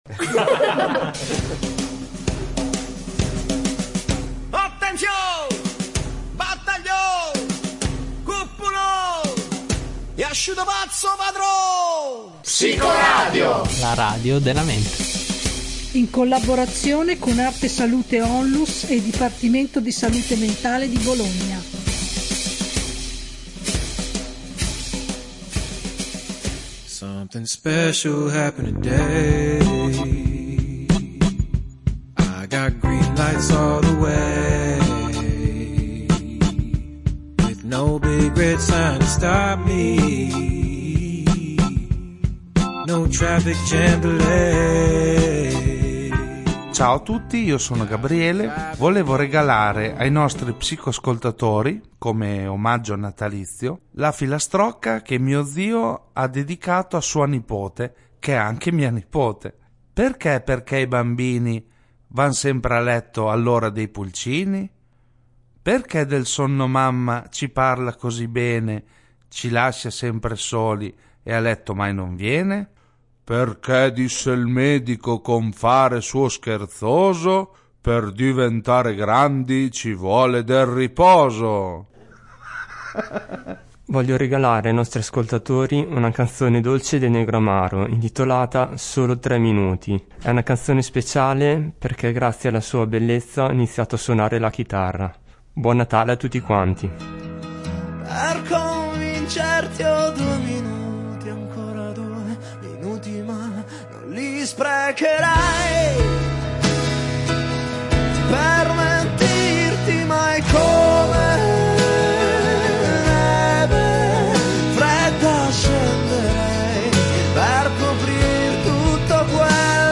In questa puntata, ogni redattore porge agli ascoltatori un regalo, un augurio, un pensiero o una riflessione che facciano pensare alla magia del Natale.
Un giudice di se stesso, perché le mamme non vengono mai a dormire, la dedica ad un papà importante, una canzone alpina cantata in diretta senza l’aiuto del vino, un Gesù bambino siciliano che dove pesta nasce il basilico….